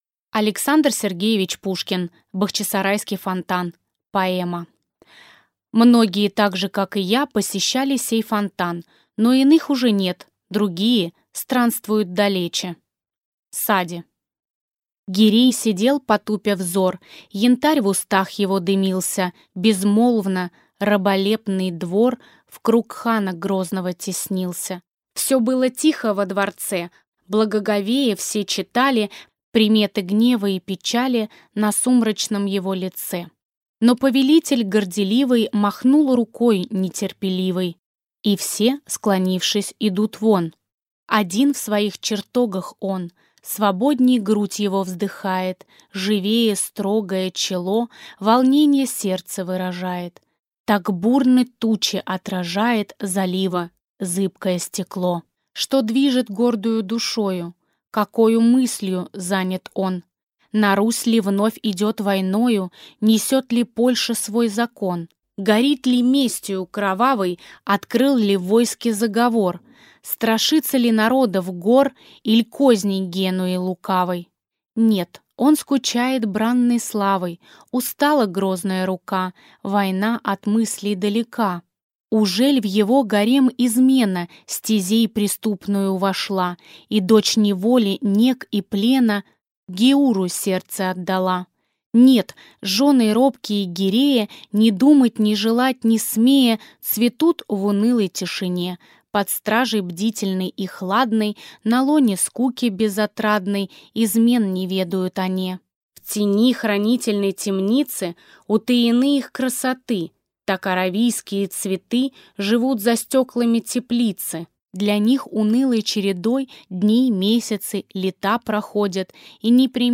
Аудиокнига Бахчисарайский фонтан | Библиотека аудиокниг